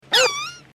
Play Perro Ladra Y Chillido - SoundBoardGuy
perro-ladra-y-chillido.mp3